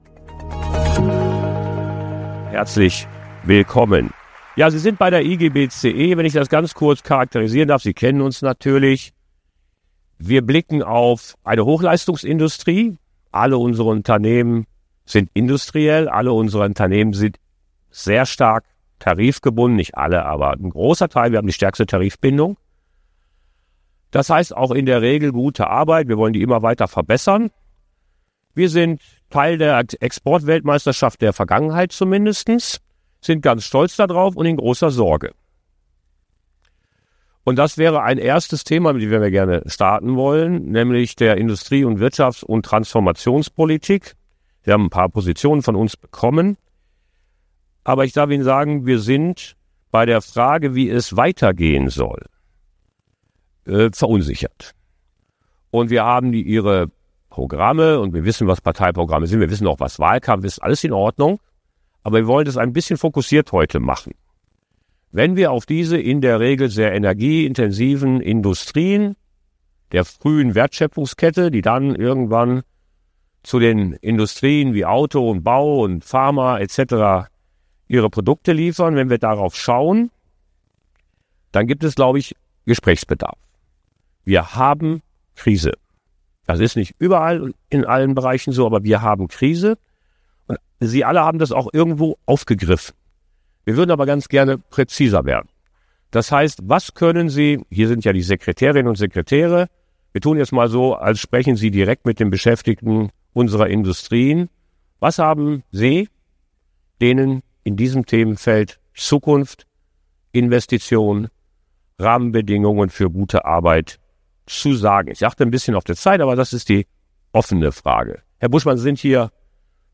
Im Wahl-Extra des Kompass-Talks hat der IGBCE-Vorsitzende Michael Vassiliadis bei Vertretern der demokratischen Parteien im Bundestag nachgefragt.